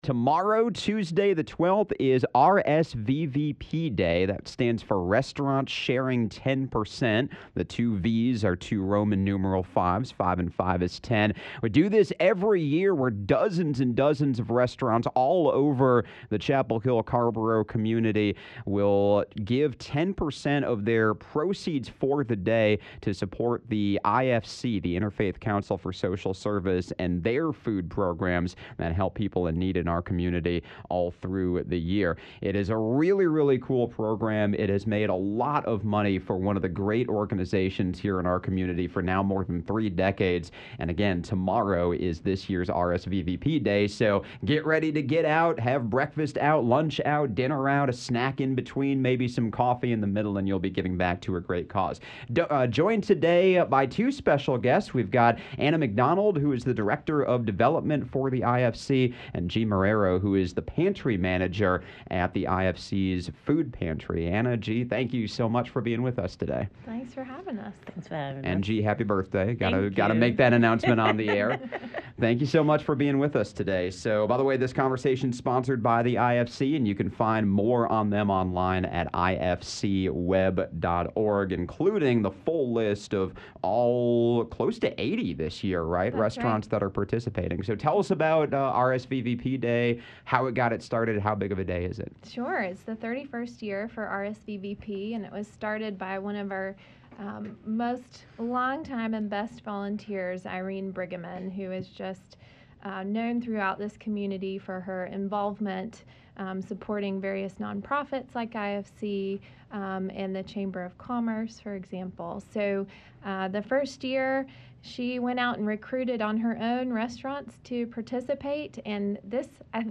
Conversation sponsored by the Inter-Faith Council for Social Service.